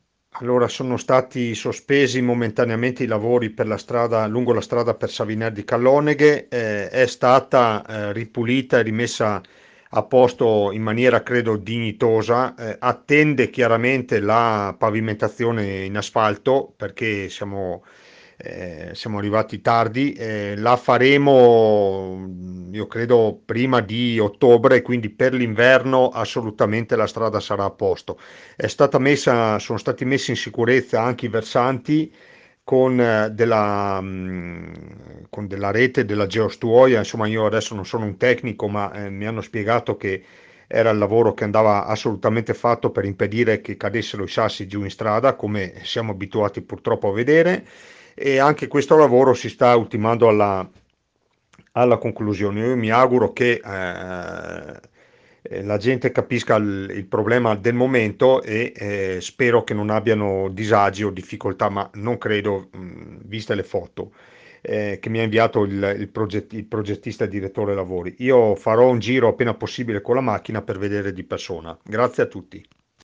A fine stagione riprenderanno i lavori con obiettivo la conclusione con la pavimentazione. IL SINDACO ANDREA DE BERNARDIN